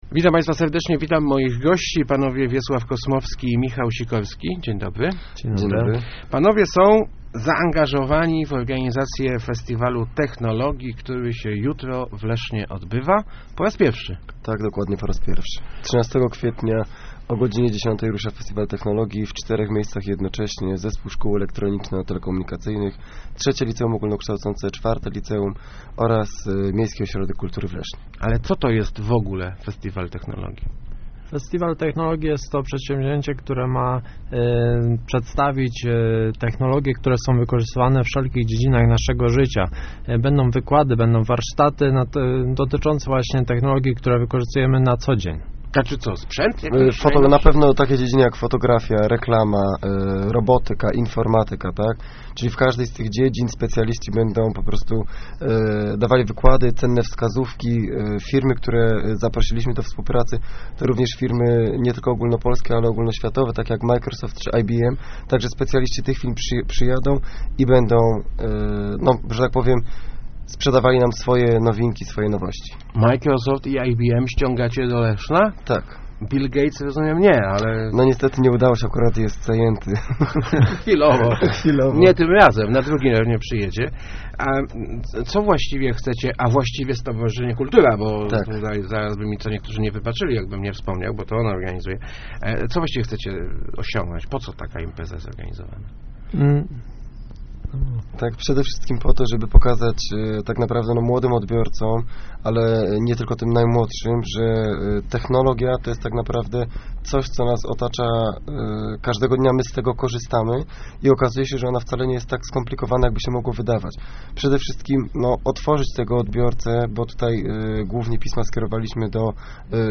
Start arrow Rozmowy Elki arrow Festiwal Technologii w Lesznie